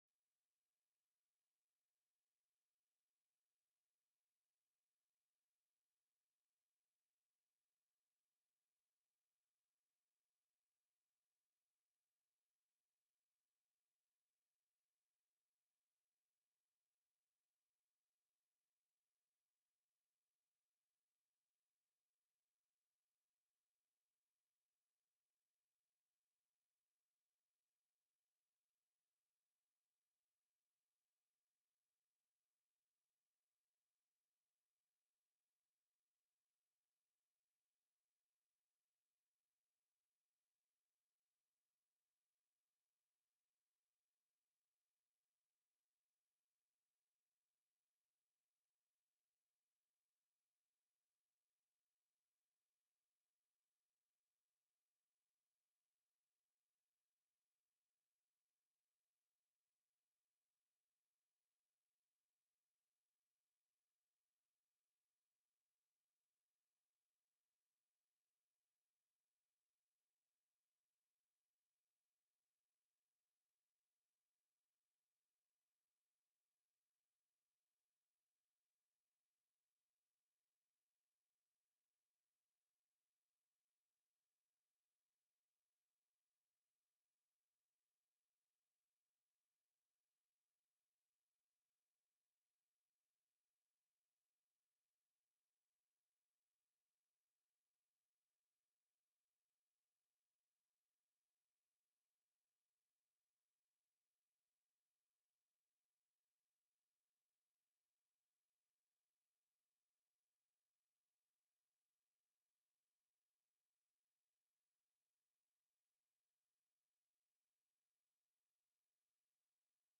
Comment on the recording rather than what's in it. The audio recordings are captured by our records offices as the official record of the meeting and will have more accurate timestamps. HB 33 CONFLICT OF INTEREST: BDS FISHERIES/GAME TELECONFERENCED Heard & Held -- Invited & Public Testimony --